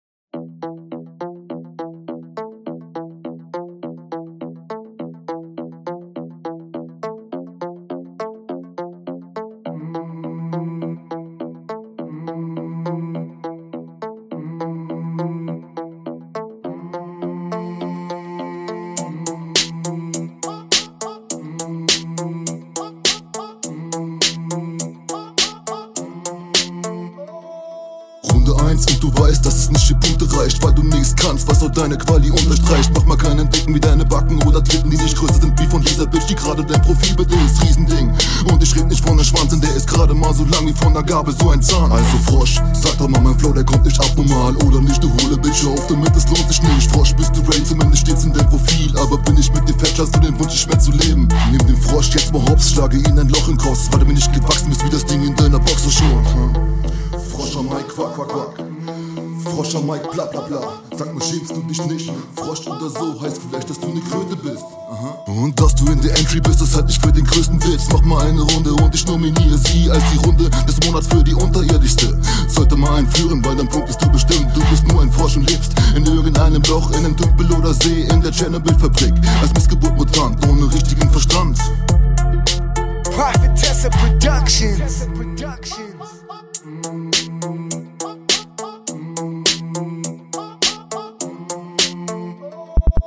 Der Stimmeinsatz ist viel zu künstlich und gedrückt, das wirkt nicht authentisch.